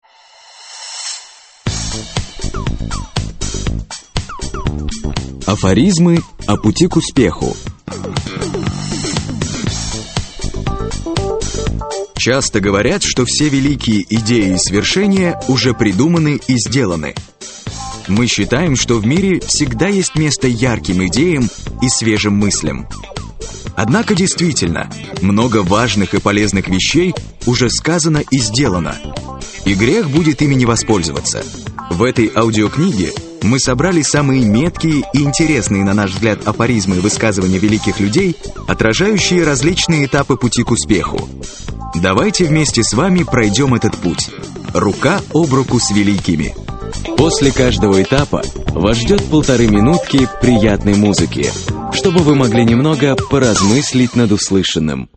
Аудиокнига Афоризмы о пути к успеху | Библиотека аудиокниг
Прослушать и бесплатно скачать фрагмент аудиокниги